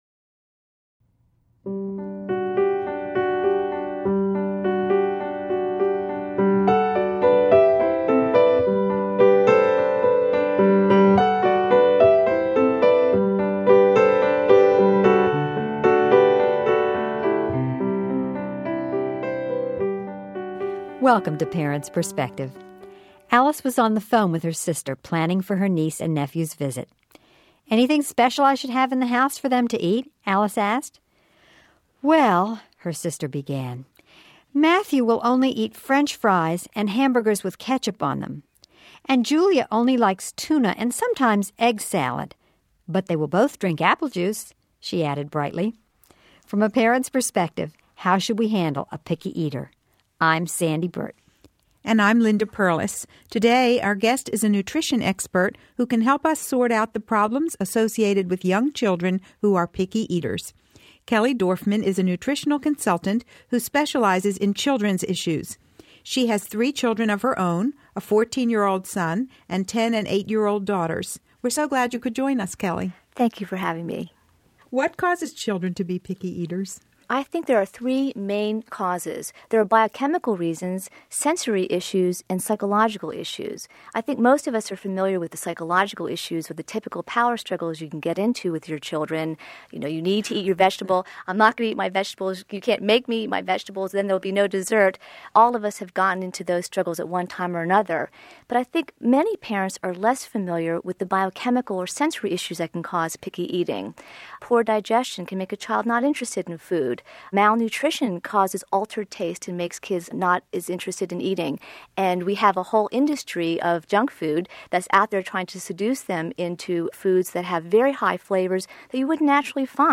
nutritional consultant specializing in children’s issues